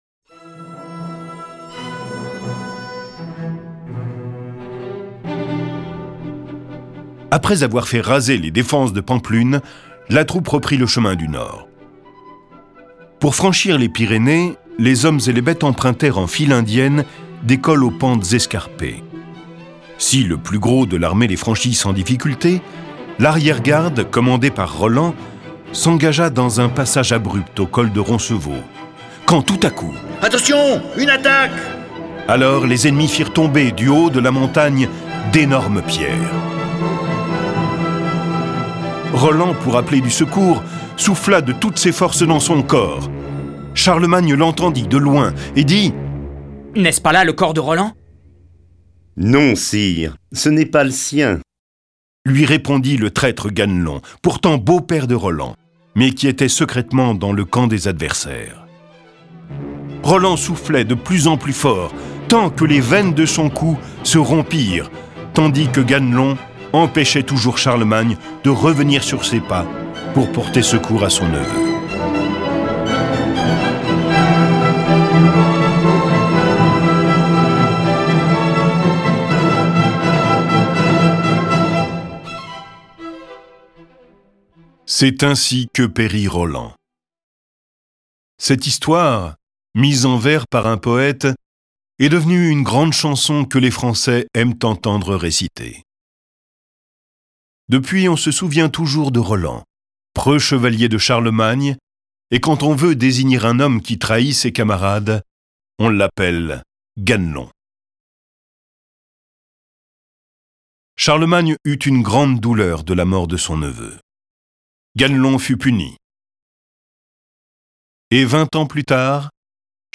Diffusion distribution ebook et livre audio - Catalogue livres numériques
Cette version sonore de ce récit est animée par neuf voix et accompagnée de plus de trente morceaux de musique classique.
Le récit et les dialogues sont illustrés avec les musiques de Bach, Bizet, Delibes, Debussy, Dvorak, Grieg, Locatelli, Marcello, Mozart, Rameau, Rossini, Tchaïkovski, Telemann, Vivaldi.